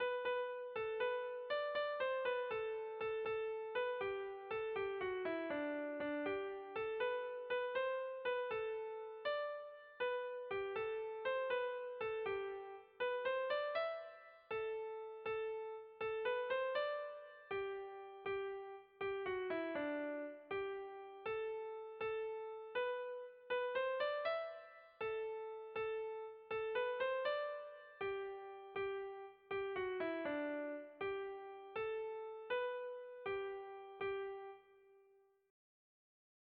Erlijiozkoa
Aspaldiko herri doinu hau asko erabiltzen da gaur egun ere elizetan.
A-B-C-C